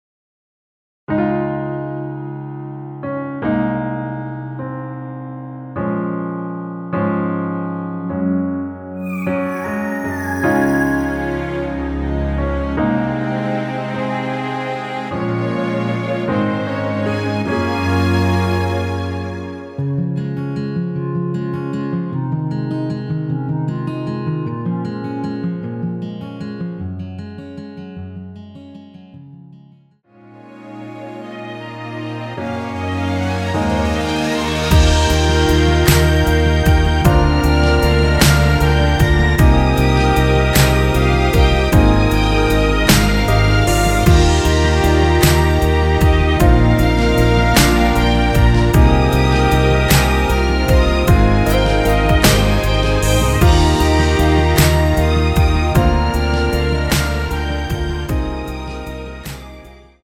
원키에서(-1)내린 멜로디 포함된 MR입니다.
Db
앞부분30초, 뒷부분30초씩 편집해서 올려 드리고 있습니다.
중간에 음이 끈어지고 다시 나오는 이유는